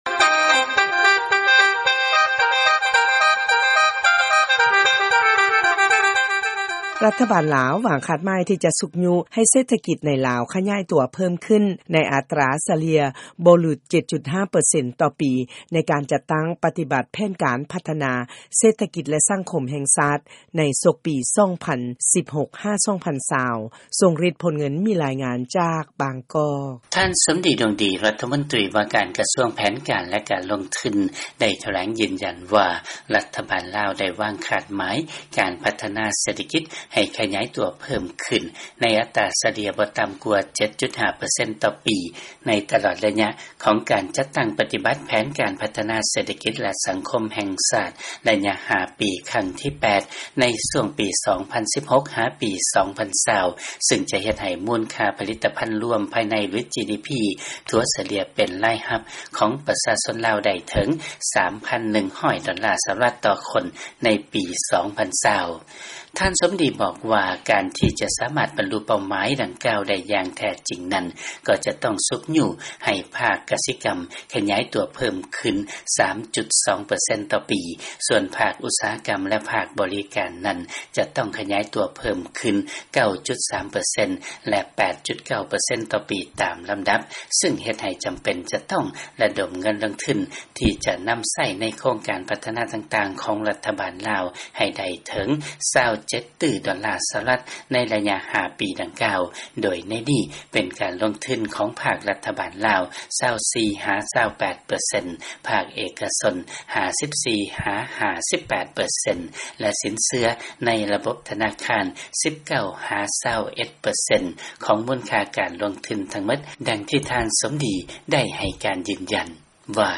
ເຊີນຟັງລາຍງານ ລັດຖະບານລາວ ຈະຊຸກຍູ້ໃຫ້ເສດຖະກິດ ໃນລາວ ຂະຫຍາຍຕົວເພີ້ມຂຶ້ນ ໃນລະດັບບໍ່ຫຼຸດ 7.5 ເປີເຊັນ.